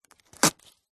Звуки билета